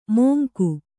♪ moła